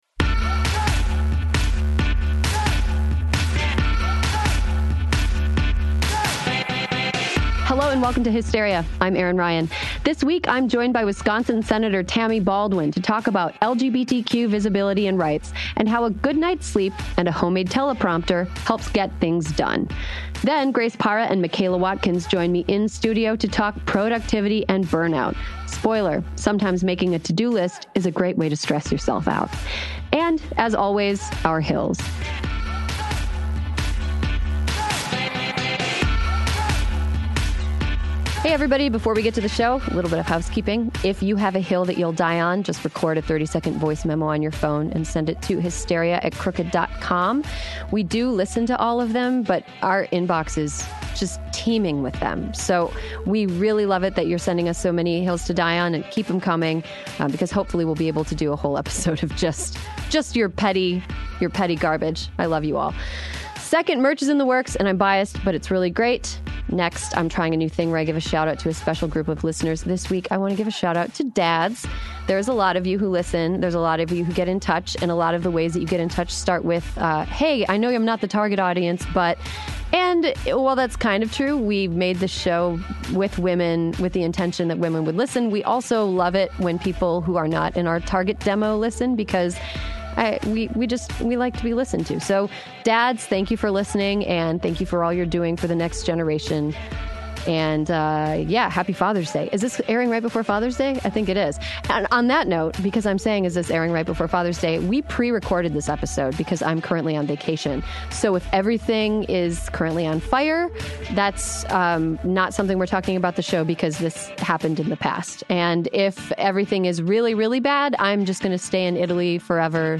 join me in-studio to talk productivity. Spoiler: sometimes making a to-do list is a great way to stress yourself out.